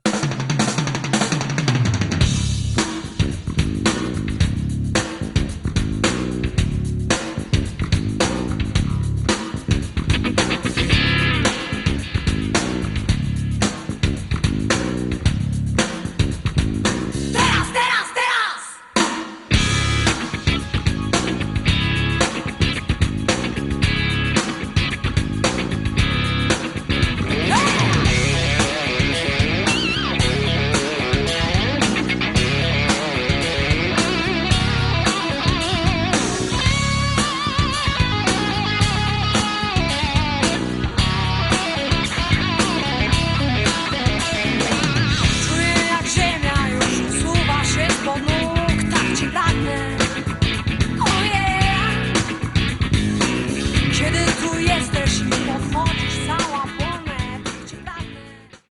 Bass
Drums
Guitar
Keyboards
Vocals
zespół wykonujący muzykę z pogranicza rocka i heavy metalu.